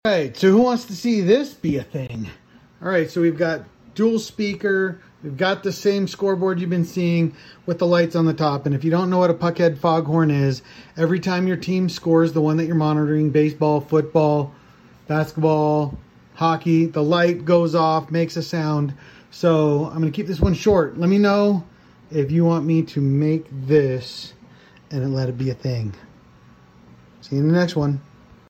A Scoreboard Foghorn?